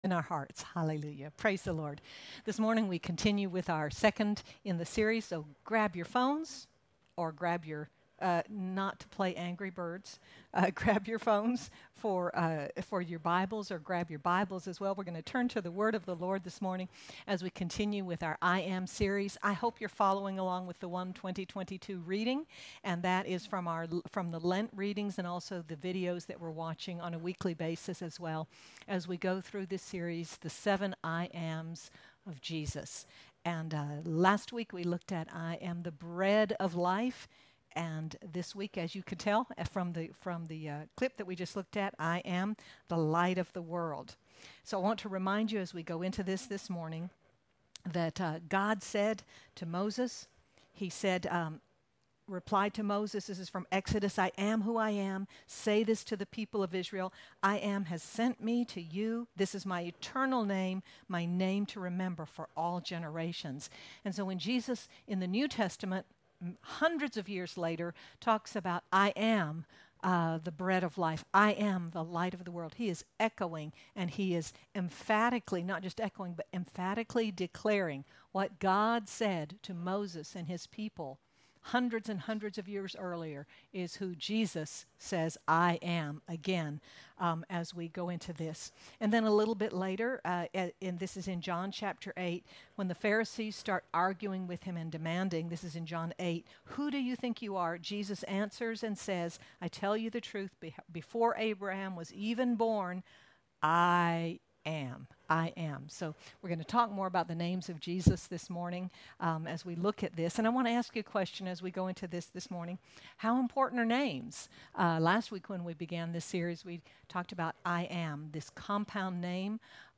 Jesus not only gives life through His light, but darkness in us is dispelled, we gain victory over the enemy, and we are guided through darkness. Sermon by